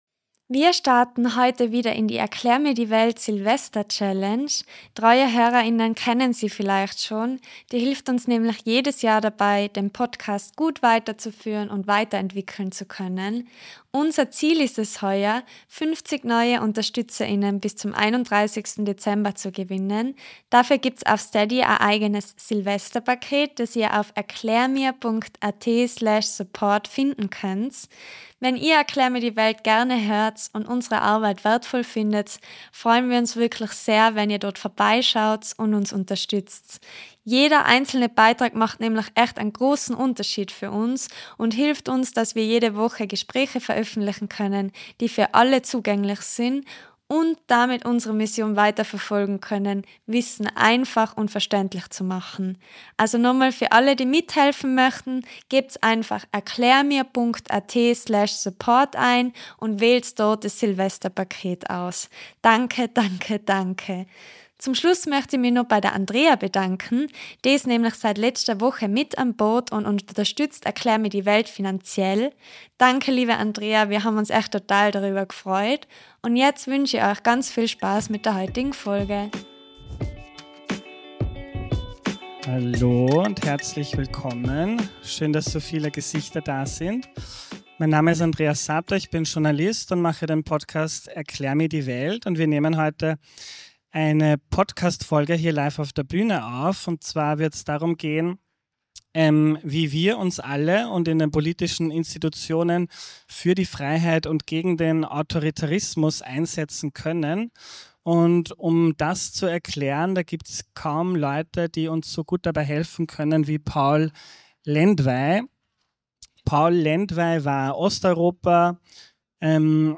Ein persönliches Gespräch über den Kampf für die Freiheit.